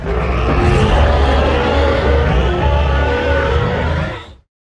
Shimoroar2.ogg